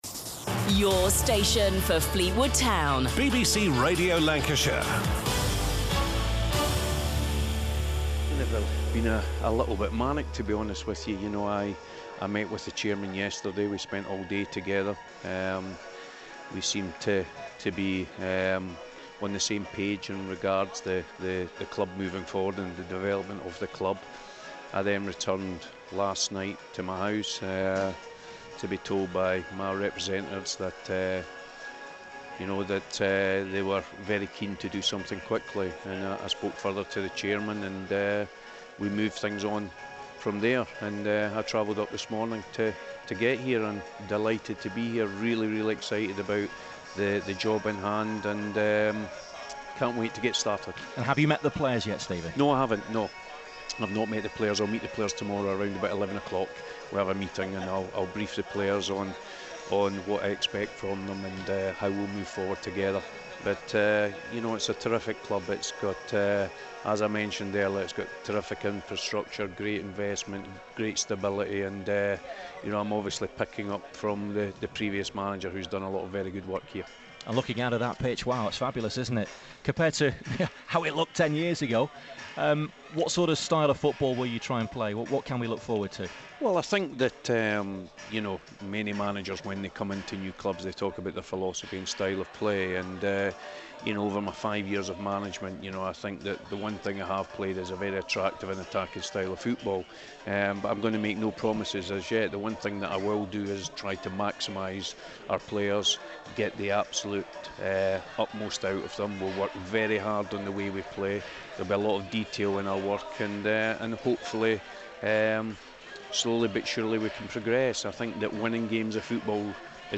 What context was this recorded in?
live on Matchnight Live